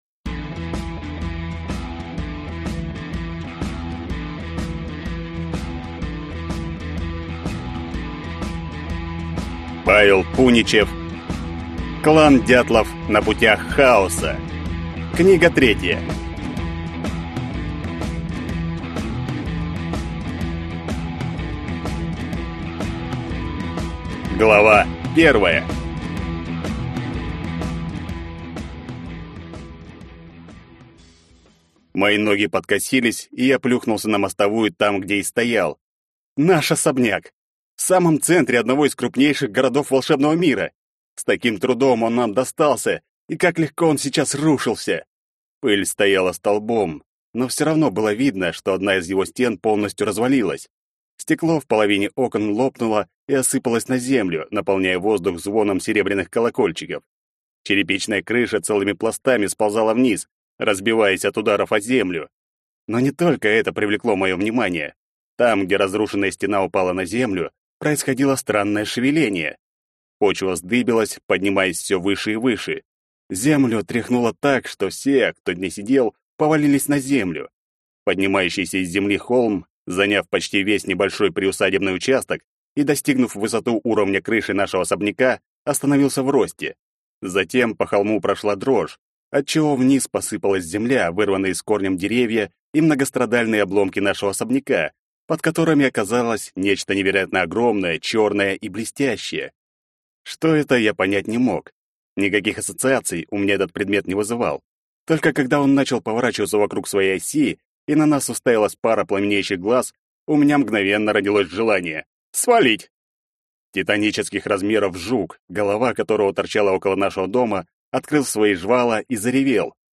Аудиокнига Клан «Дятлов». Книга 3. На путях Хаоса | Библиотека аудиокниг